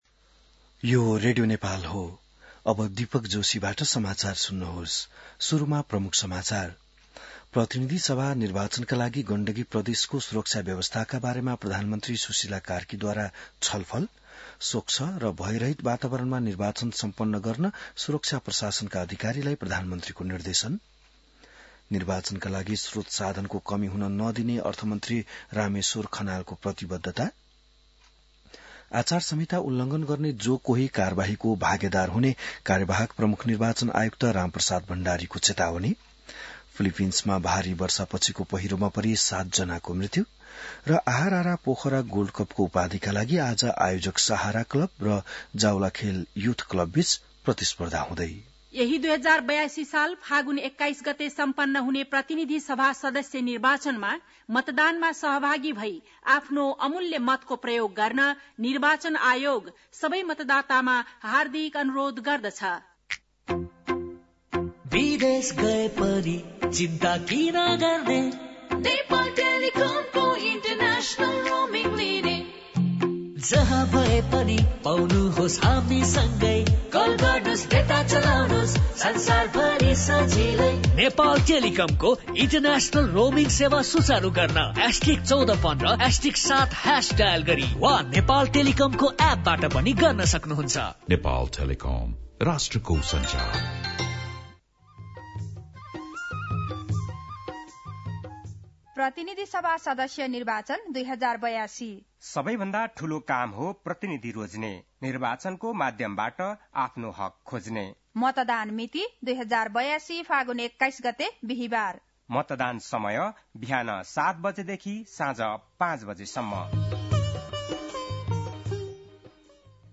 बिहान ७ बजेको नेपाली समाचार : ९ फागुन , २०८२